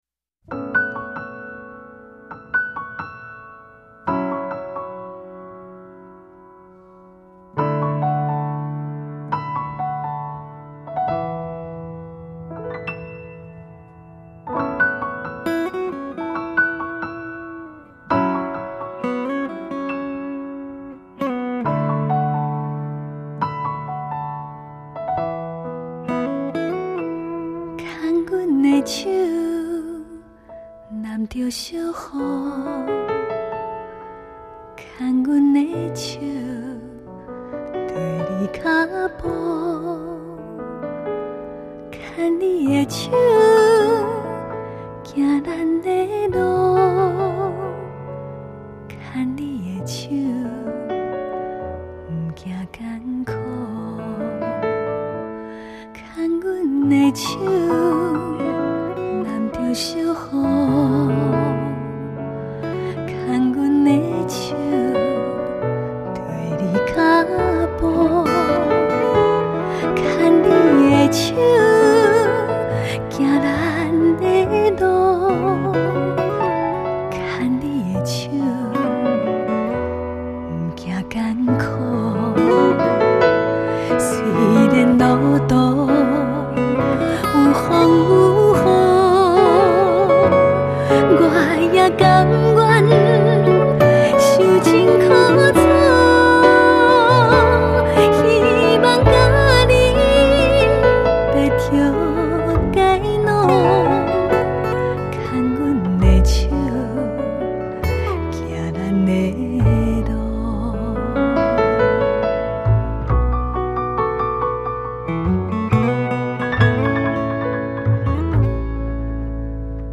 让我们沉醉在她甜美的声音，仿佛回到了抒情又复古的迷人年代回味最美的台湾歌声！